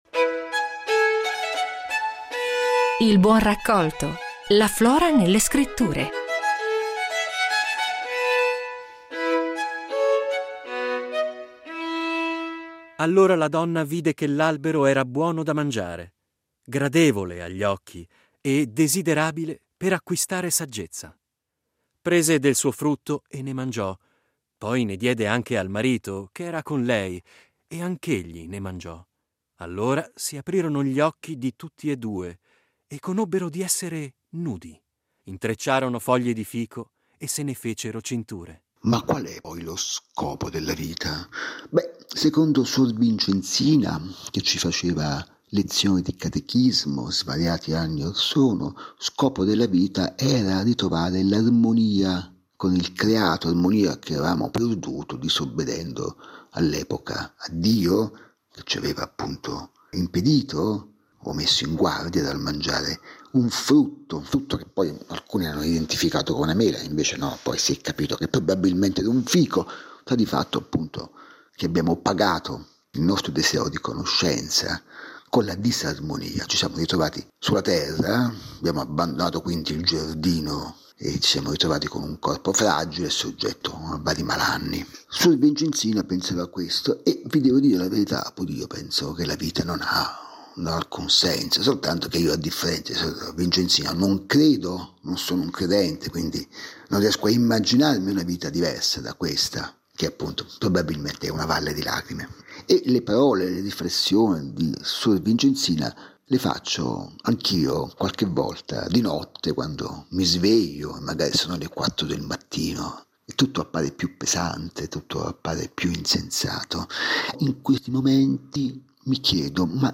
Presentato da Antonio Pascale